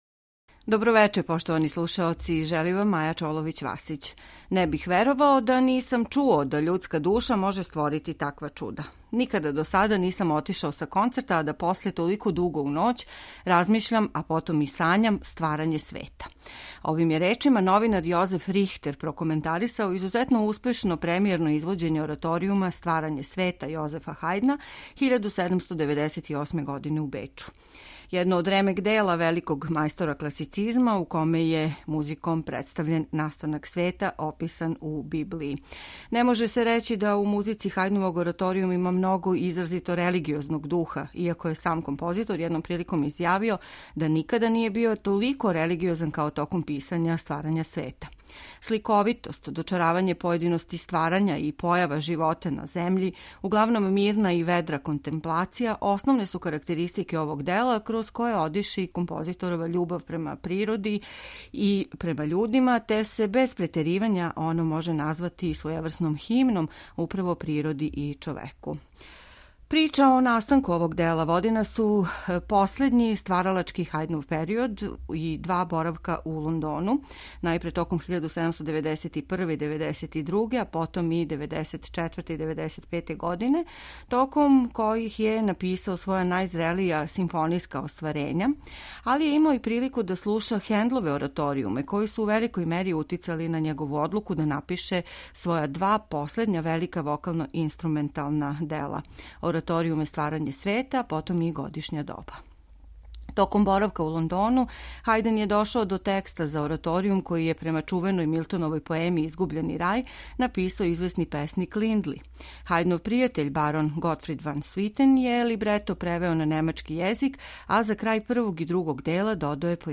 У вечерашњој емисији ћете слушати хорске нумере у извођењу реномираних солиста и Друштва Хендла и Хајдна, којима диригује Хари Кристоферс.